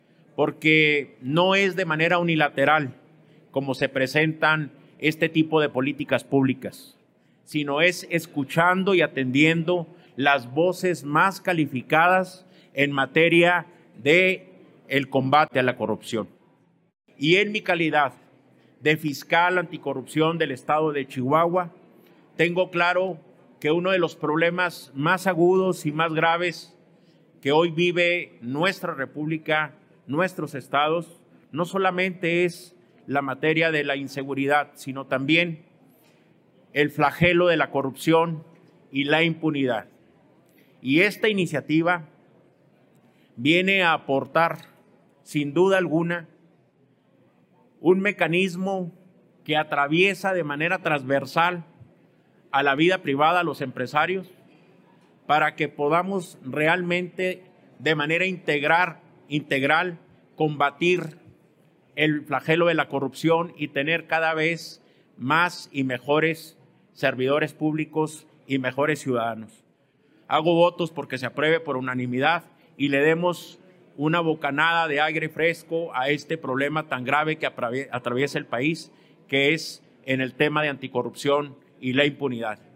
A través de una rueda de prensa llevada a cabo en la Cámara Alta, Valenzuela Holguín aseveró que el Gobierno del Estado de Chihuahua ha elaborado política públicas efectivas en contra de la corrupción, las cuales han resultado en múltiples certificaciones ante organismos internacionales como Transparencia Internacional, OECD, embajadas de Estados Unidos y Alemania.